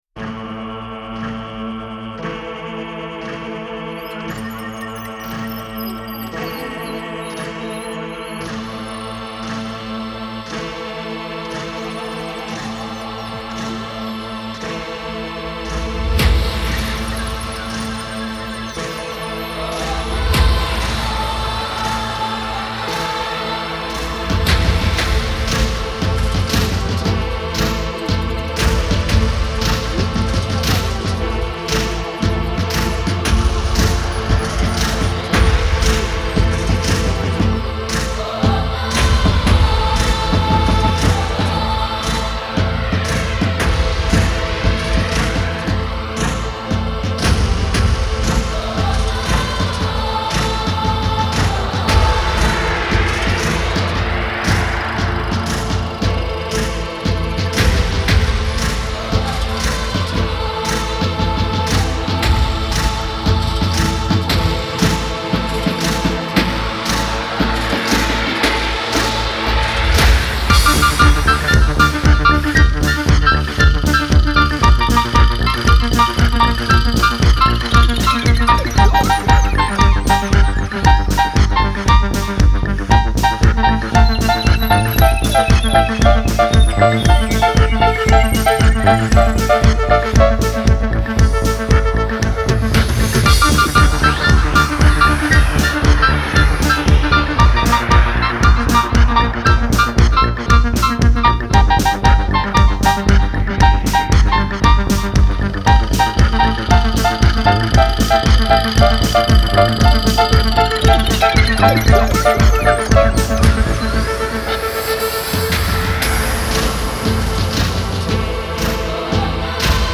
Genre : Électronique, Techno